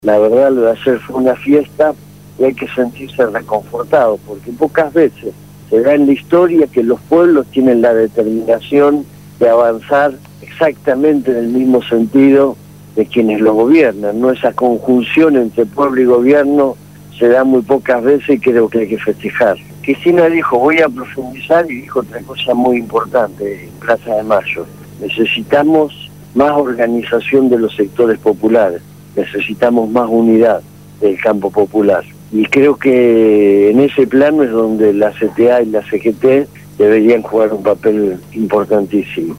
Entrevista a Hugo Yasky, Secretario General CTA de los Trabajadores